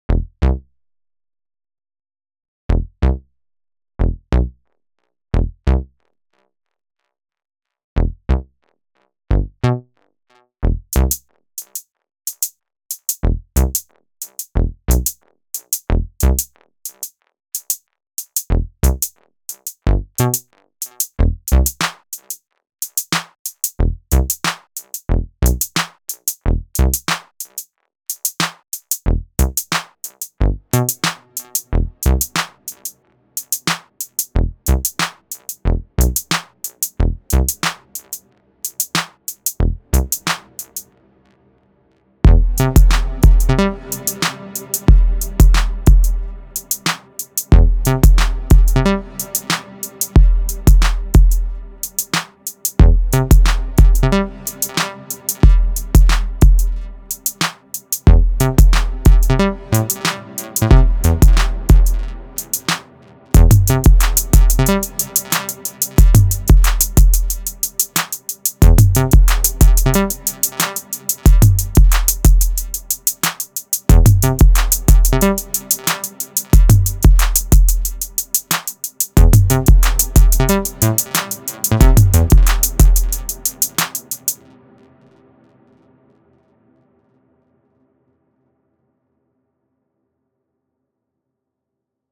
Here is a little noddle with 1 layer, the arp, some of the onboard FX, + an 808. Added some compression on the main :slight_smile: